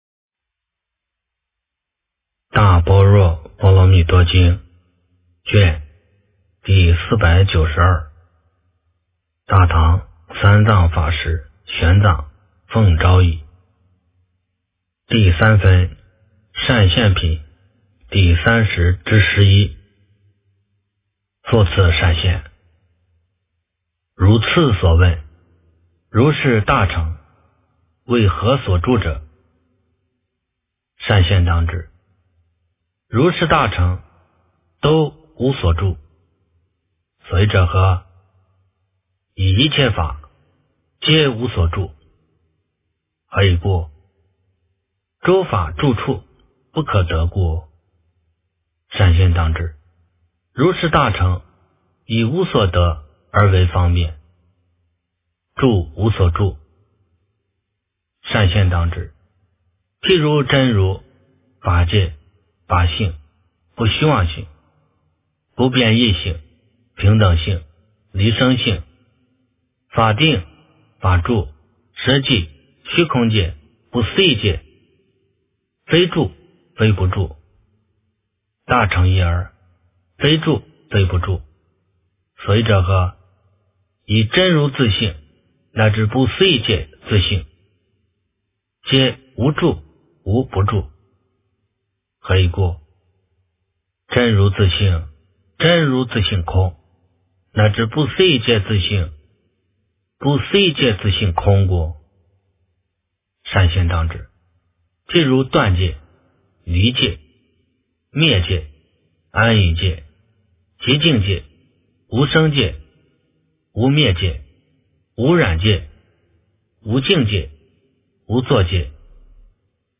大般若波罗蜜多经第492卷 - 诵经 - 云佛论坛